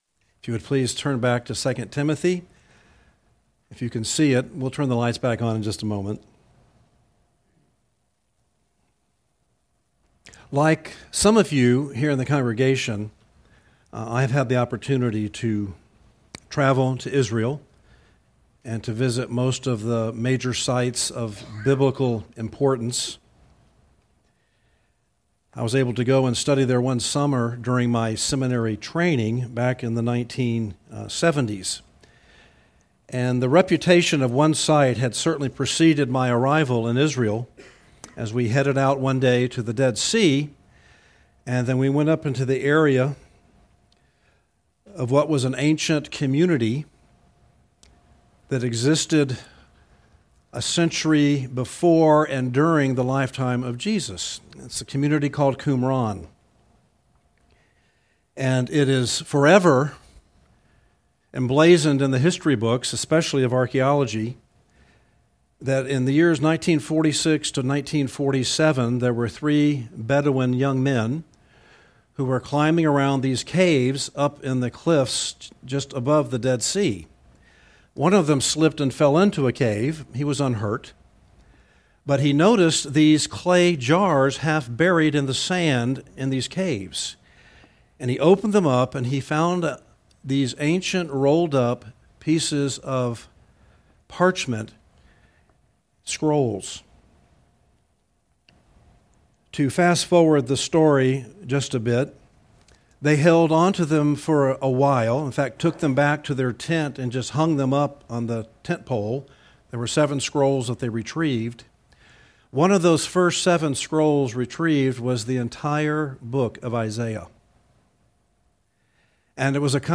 teaches from the series: 2 Timothy, in the book of 2 Timothy, verses 1:13 - 2:2